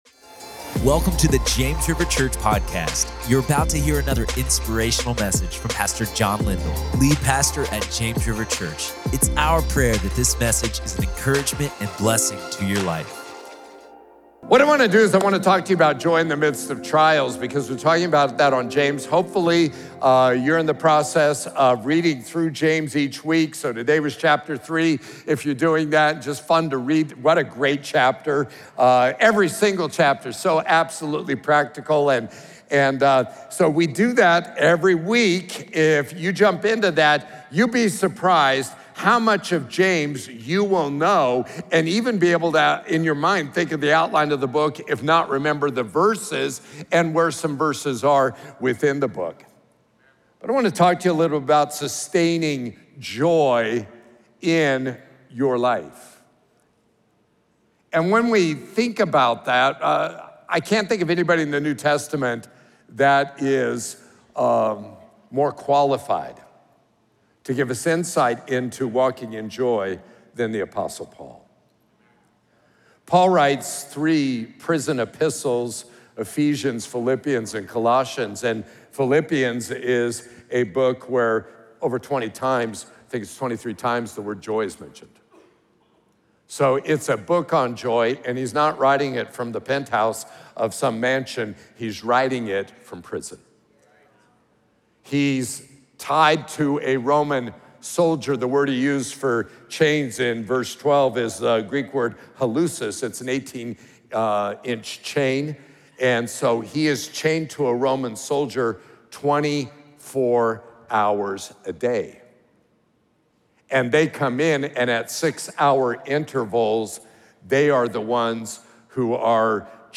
Joy In The Midst Of Trials | Prayer Meeting